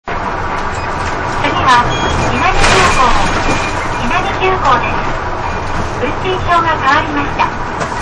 南吸江MpegAudio(39kb) みなみぎゅうこう 土佐電鉄 ＪＲ土讃線　朝倉駅
minami-gyuko.mp3